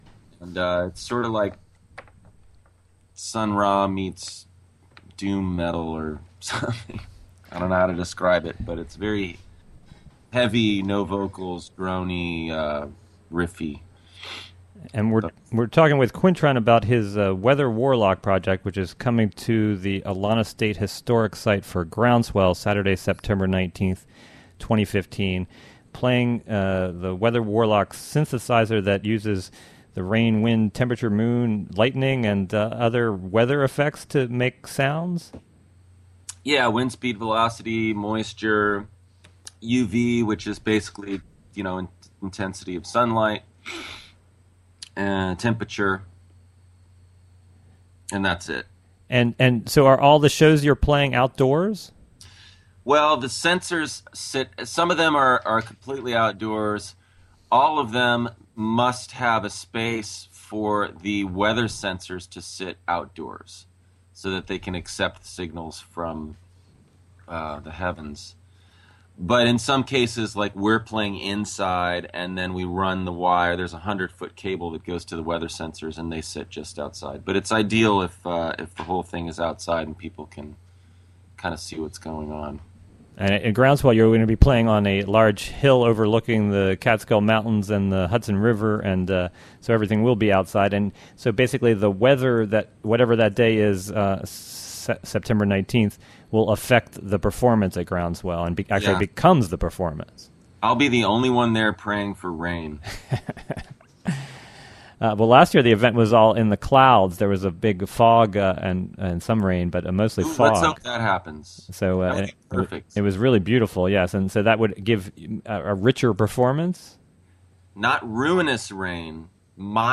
10:30 am Morning local radio show about local radio.
Quintron Interviewed